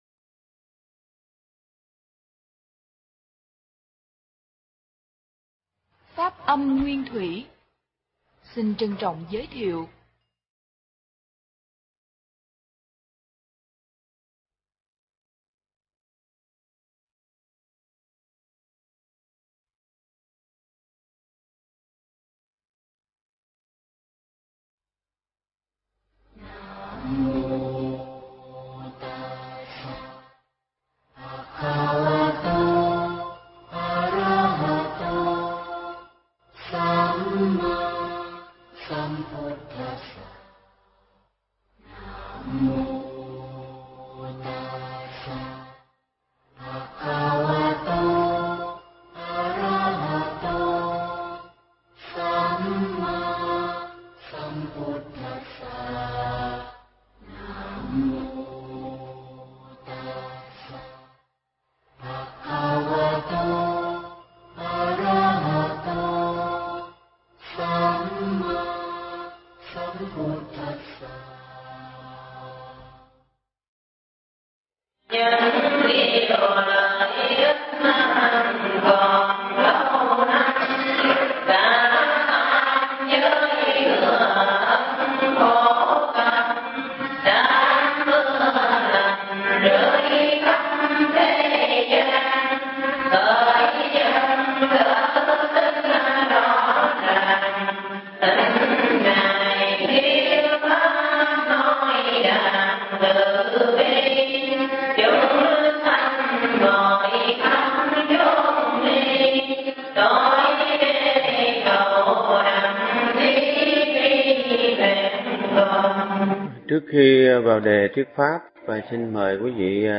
Nghe Mp3 thuyết pháp Năm Hết Chết Đến